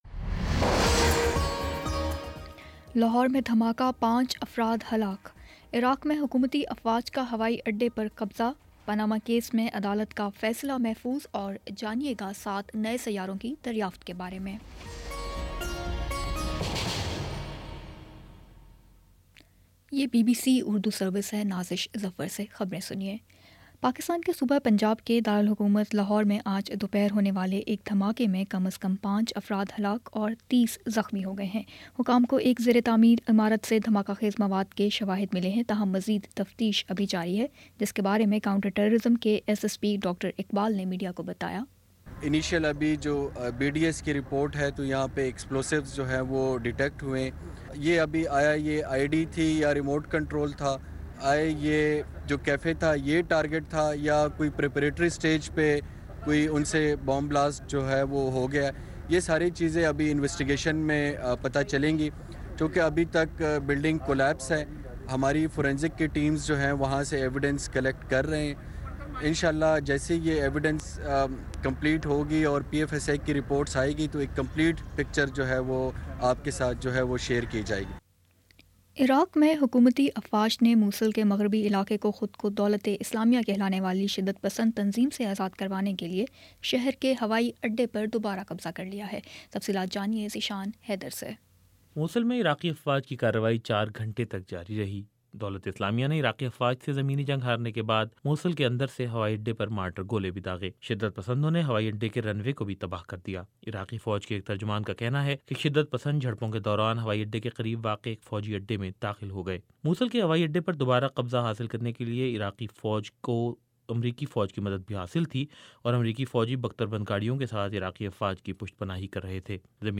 فروری 23 : شام چھ بجے کا نیوز بُلیٹن